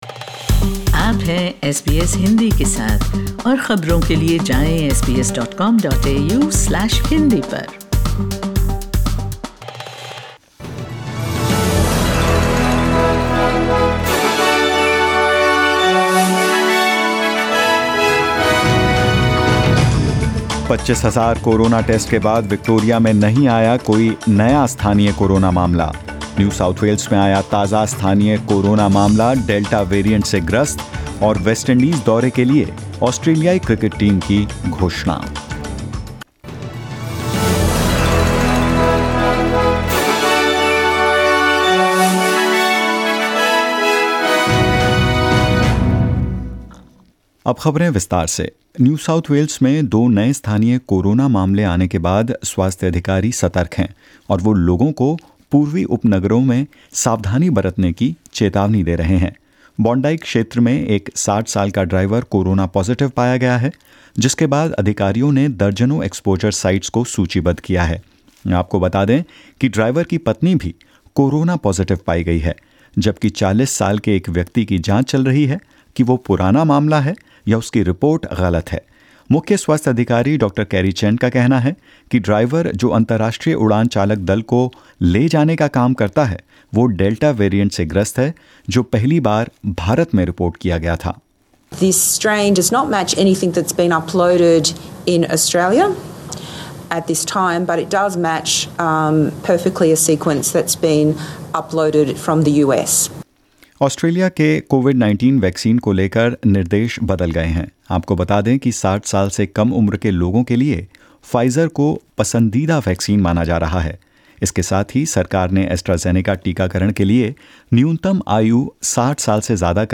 In this latest SBS Hindi News bulletin of Australia and India: Sydney's COVID-19 outbreak grows to three cases; Australian batsman, Steve Smith Reclaims Number 1 Spot On ICC Test Rankings, Virat Kohli In Fourth Spot, and more.